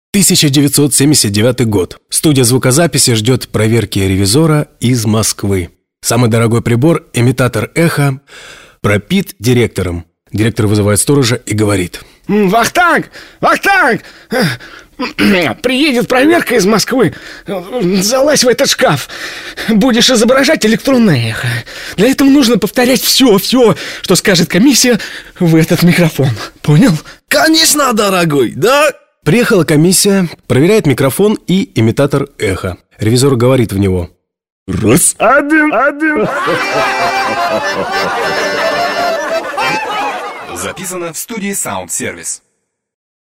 Сотрудники студии звукозаписи «СаундСервис» в честь праздника создали и записали несколько приколов и шуток.